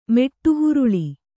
♪ meṭṭu huruḷi